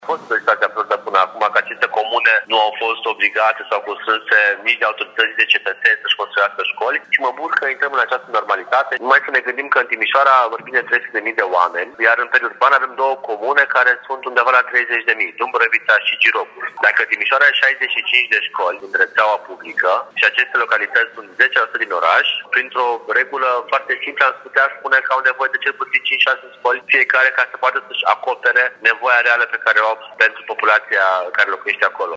Construcția unor noi școli în comunele învecinate Timișoarei va degreva nu doar sistemul educațional din municipiu, ci și traficul, spune viceprimarul Ruben Lațcău.